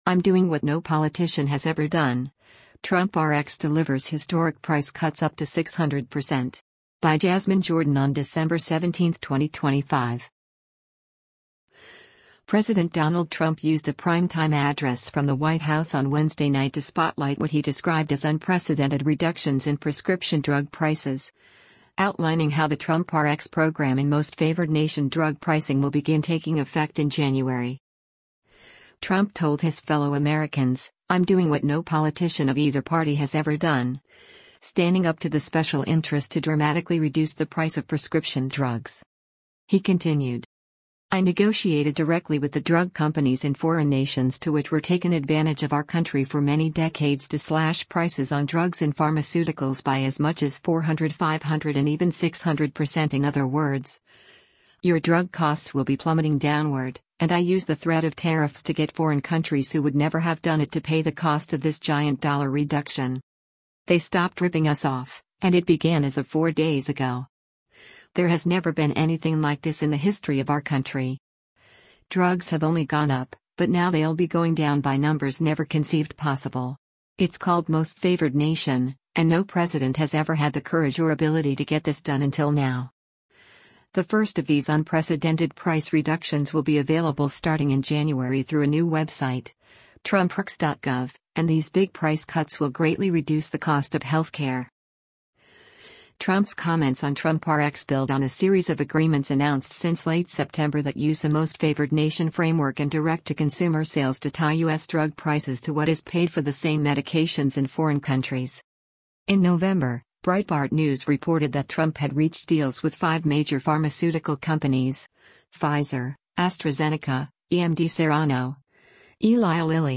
President Donald Trump used a prime time address from the White House on Wednesday night to spotlight what he described as unprecedented reductions in prescription drug prices, outlining how the TrumpRx program and most-favored-nation drug pricing will begin taking effect in January.